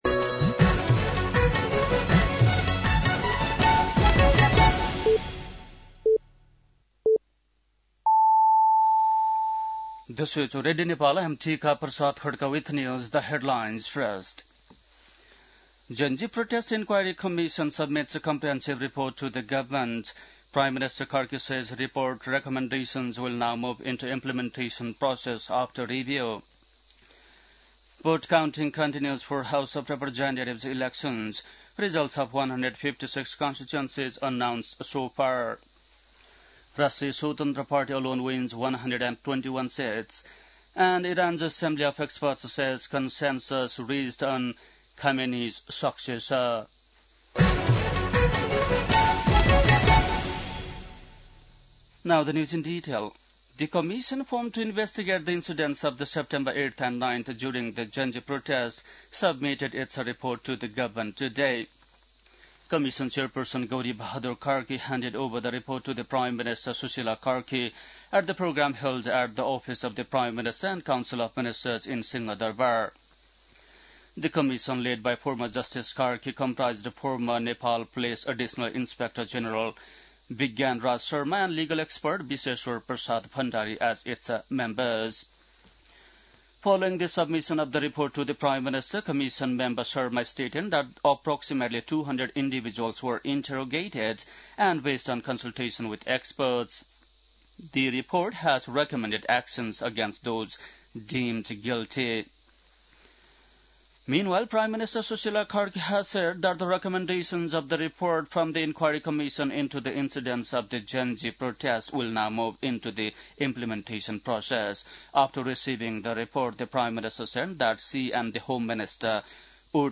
An online outlet of Nepal's national radio broadcaster
बेलुकी ८ बजेको अङ्ग्रेजी समाचार : २४ फागुन , २०८२
8-pm-english-news-.mp3